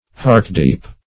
Heartdeep \Heart"deep`\ (-d[=e]p`), a. Rooted in the heart.